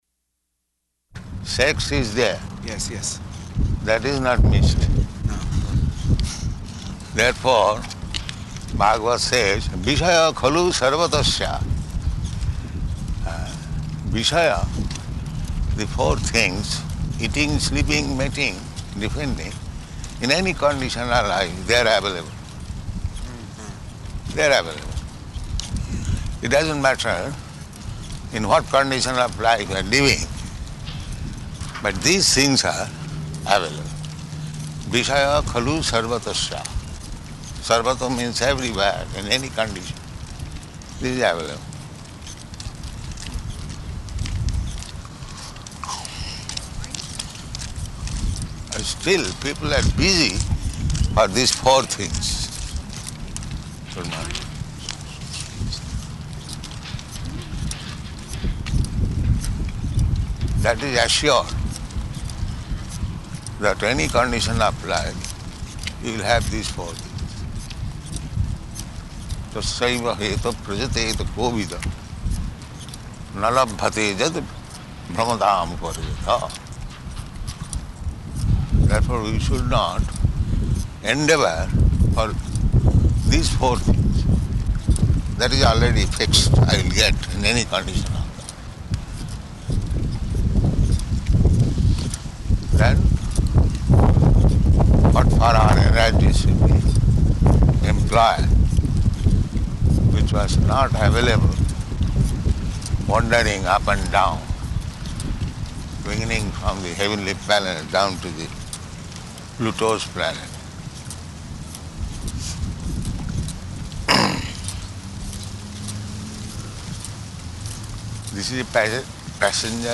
Morning Walk --:-- --:-- Type: Walk Dated: January 23rd 1974 Location: Honolulu Audio file: 740123MW.HON.mp3 Prabhupāda: ...that sex is there.